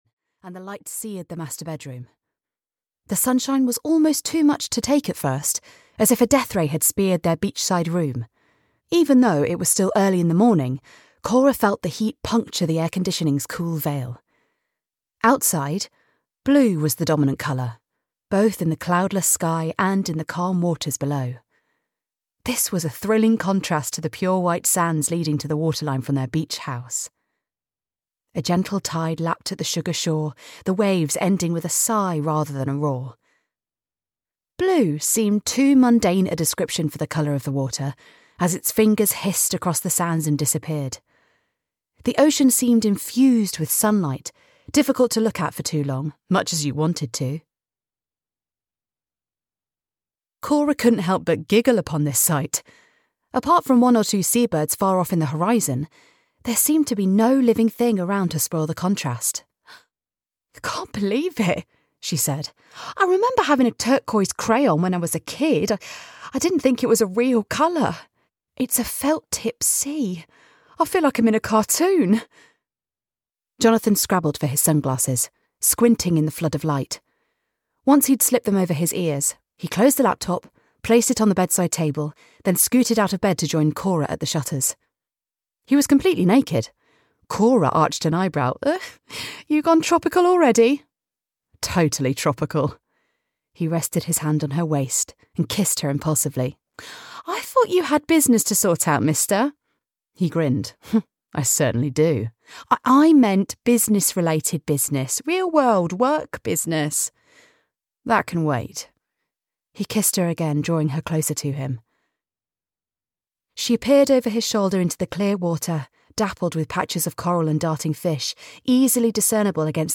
The Beach House (EN) audiokniha
Ukázka z knihy